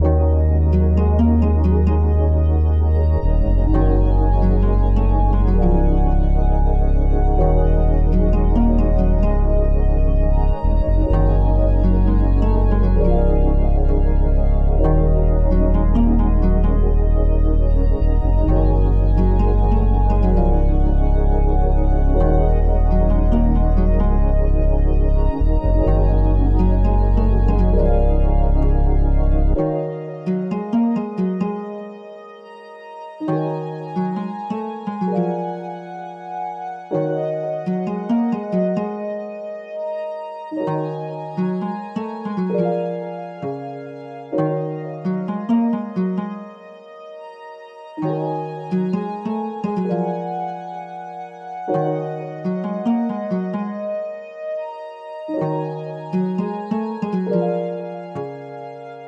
• High-Quality RnB Samples 💯